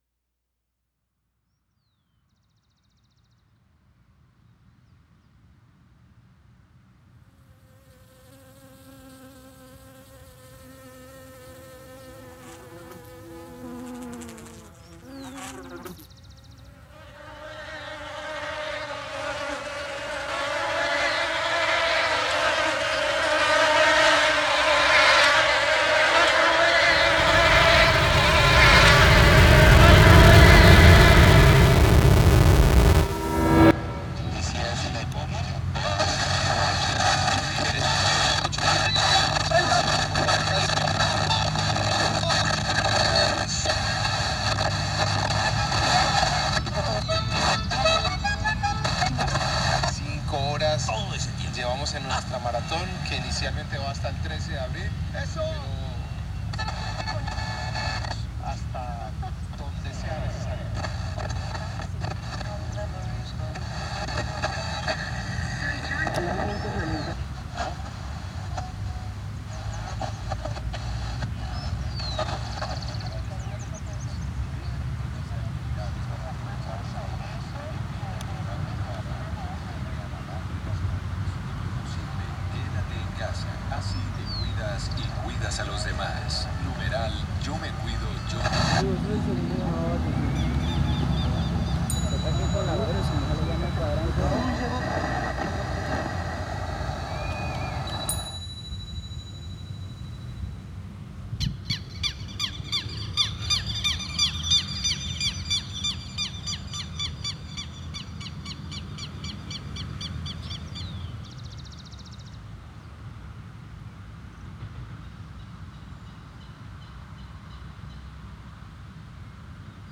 OBRAS RADIOFÓNICAS
Este paisaje sonoro pretende hacer interpretación a este caos digital del que todos hacemos parte, dando pequeños respiros de libertad y de relajación profunda para aligerar esa fatiga generada por el exceso de información con el que somos bombardeados incesantemente a través de la hiper comunicación digital, que solo busca hacerse al control de nuestros pensamientos e influenciarlos para modificarlos de manera inconsciente.